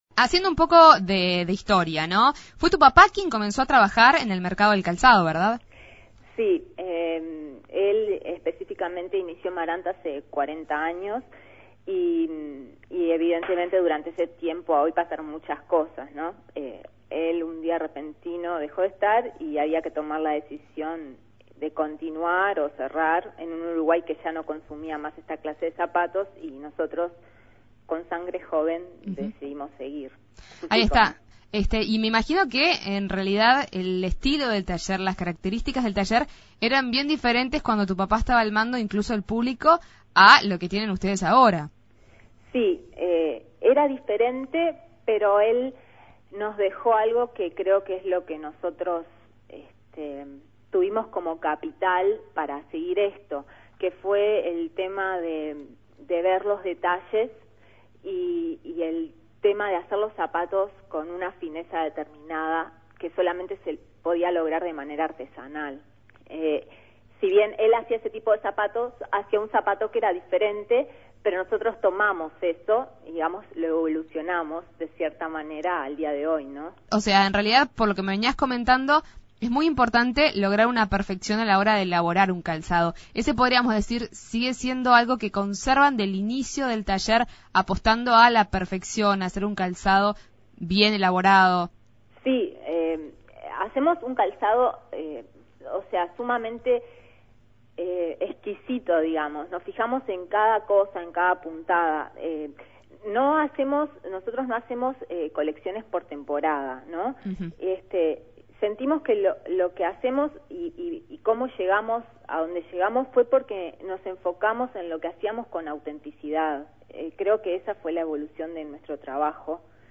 fue entrevistada en la Segunda Mañana de En Perspectiva.